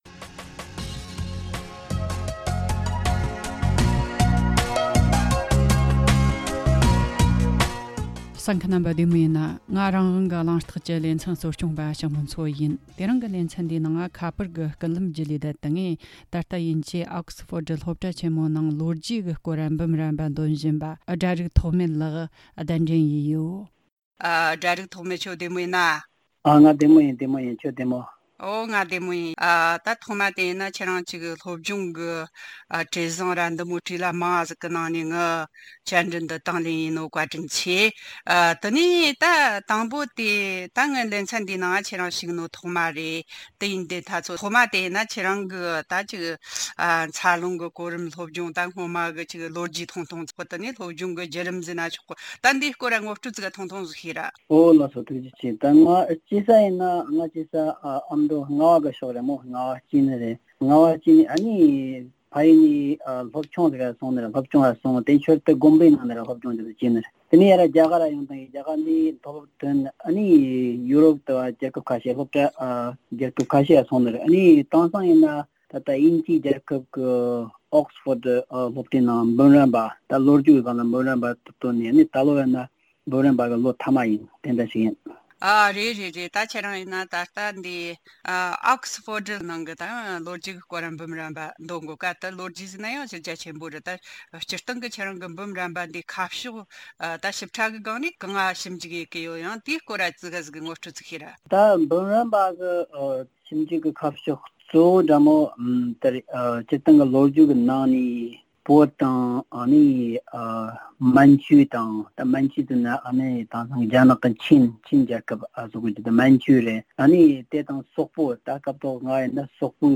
ཕྱོགས་བསྡུས་ཞུས་པའི་གནས་ཚུལ།